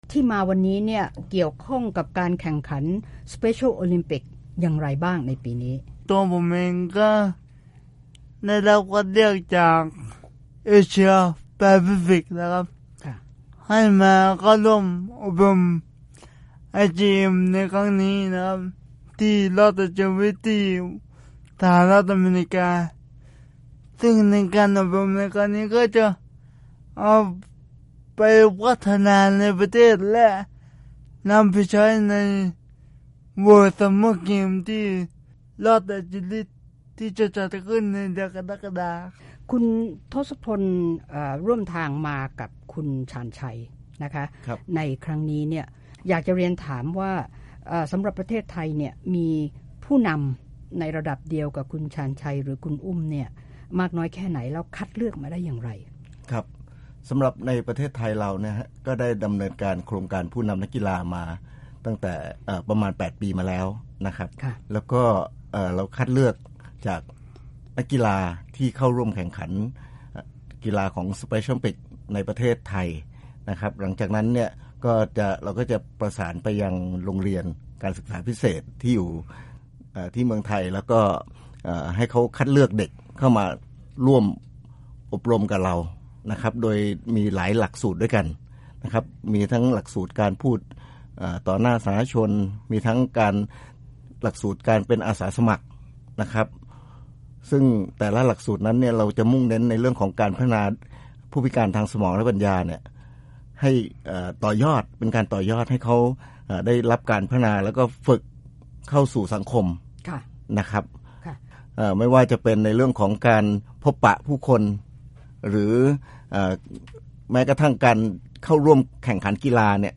รายงานสัมภาษณ์ผู้แทนไทยใน Special Olympics ตอนที่ 1